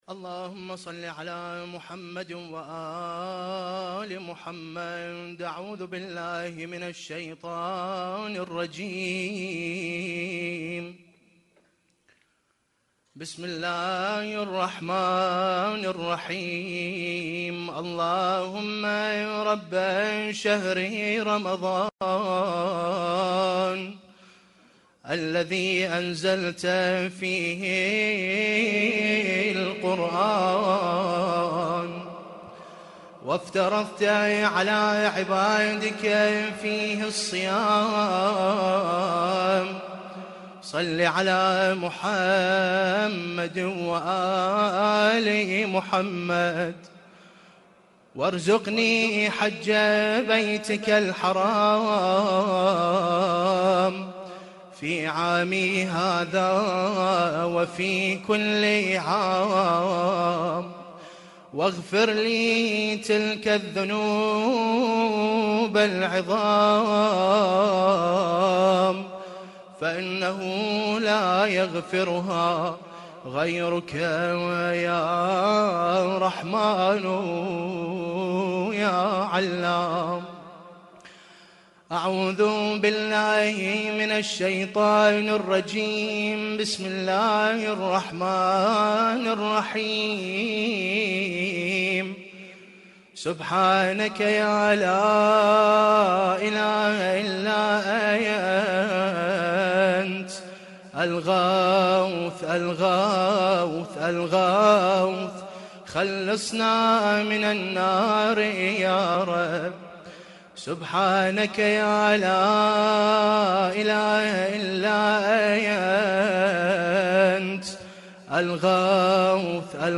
دعاء الجوشن الكبير
الرادود